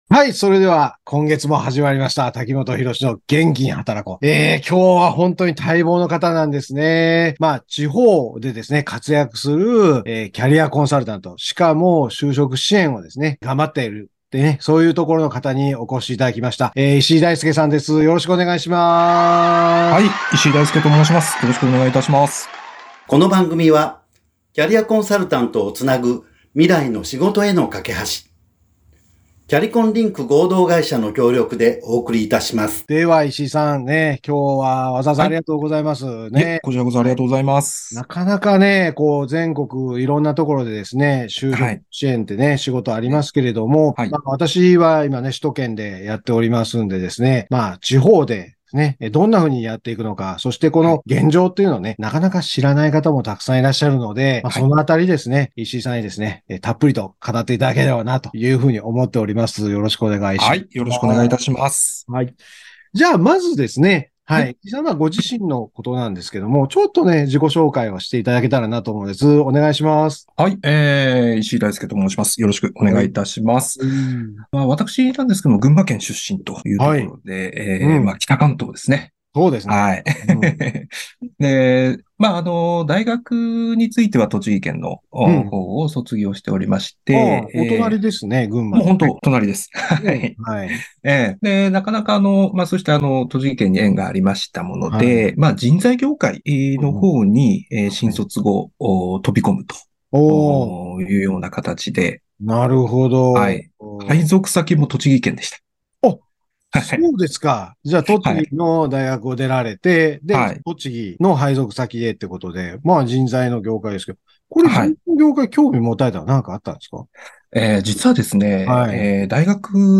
FM79.7京都三条ラジオカフェ2023年11月9日放送分です。